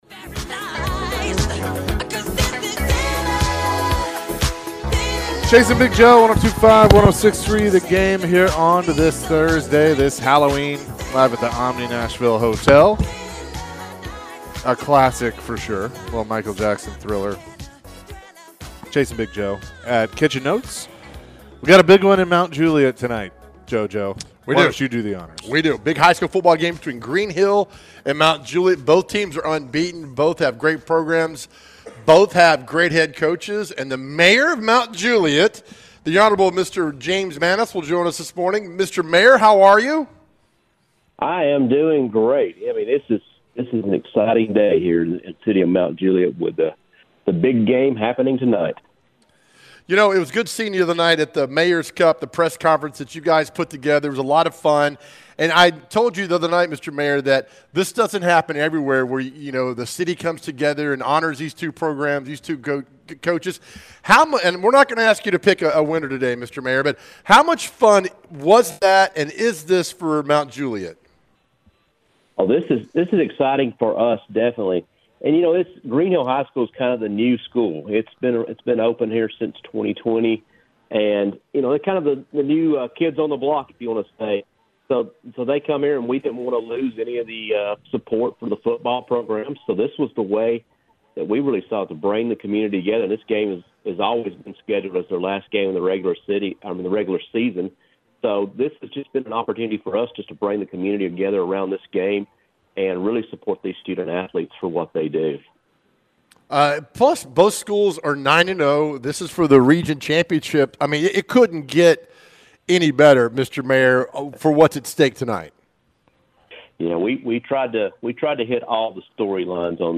Mt. Juliet Mayor James Maness joined the show previewing a big high school football game tonight. who will win the Mayor's Cup?